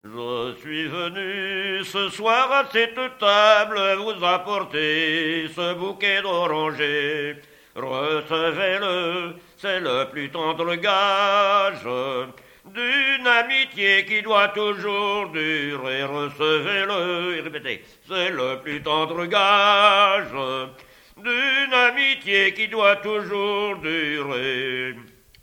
circonstance : fiançaille, noce
Chansons traditionnelles
Pièce musicale inédite